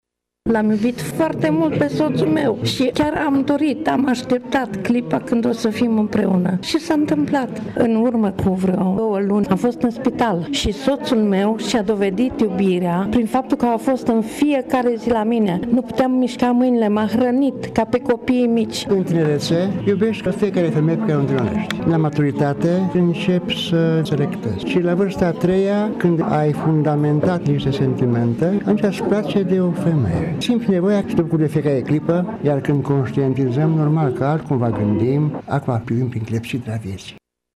Ambii spun că au ajuns la un anumit tip de maturitate, iar atunci iubirea se sedimentează: